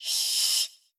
deepInhale.wav